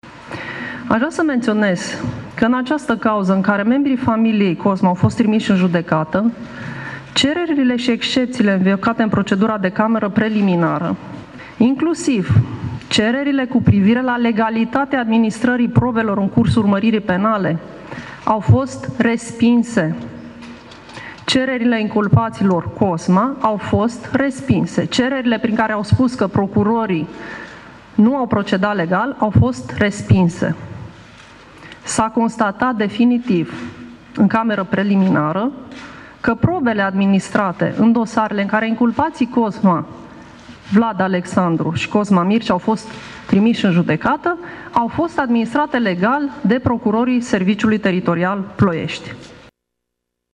Laura Codruța Koveși a spus că judecătorii au considerat legale probele DNA în cazul Cozma: